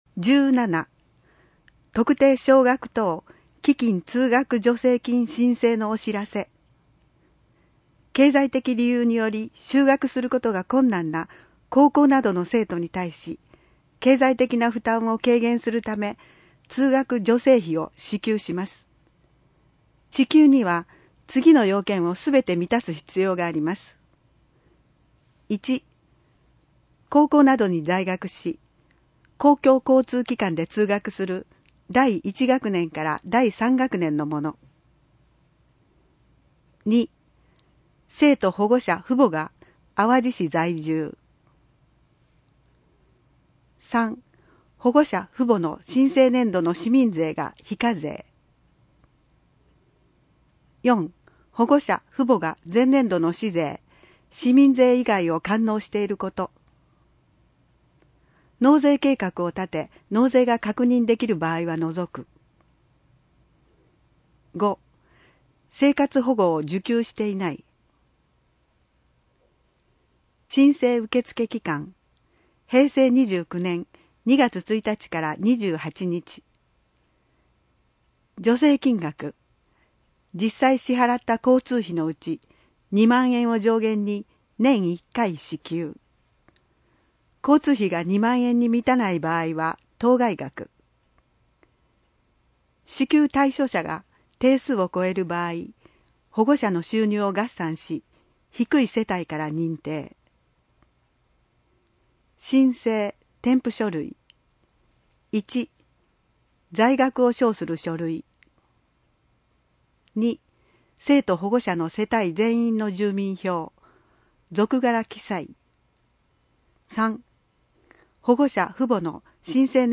朗読　東浦朗読ボランティアグループ・ひとみの会